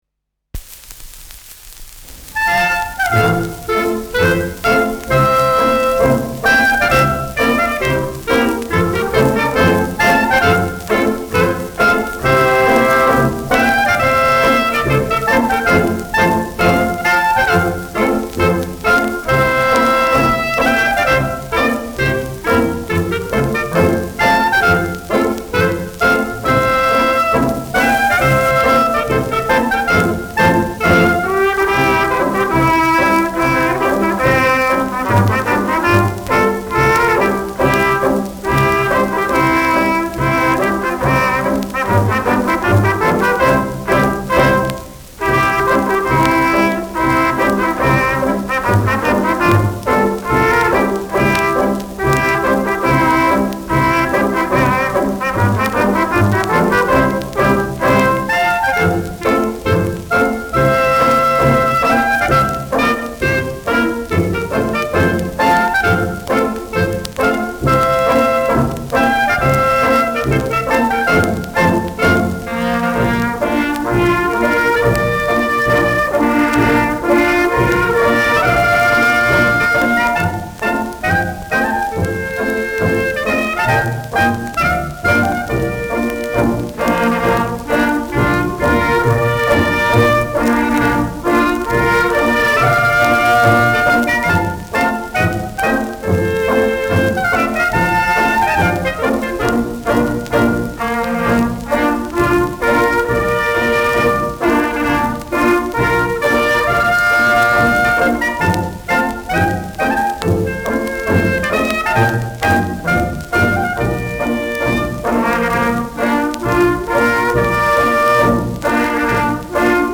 Schellackplatte
leichtes Rauschen : leichtes Knistern
[Berlin] (Aufnahmeort)